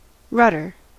Ääntäminen
IPA : /ˈɹʌdə(ɹ)/ US : IPA : /ˈɹʌdɚ/